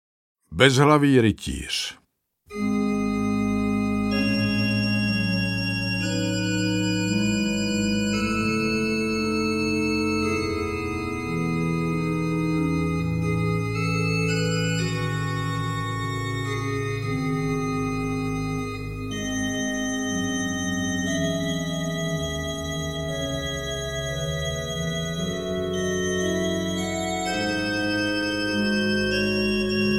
25 známých českých strašidel představuje audiokniha pověstí určených malým i velkým posluchačům! Herec Jan Kanyza vypráví příběhy z různých míst Čech i Moravy plné strašidel a nadpřirozených bytostí. Bílá paní, bezhlavý rytíř, vodník, bludičky, čert, divoženky, vlkodlak a řada dalších tajemných postav vystupuje ve strašidelných pověstech.
Herec Jan Kanyza je považován za jednoho z nejcharismatičtějších českých herců a jeho výrazný a charakteristický hlas se skvěle hodí pro interpretaci historických textů.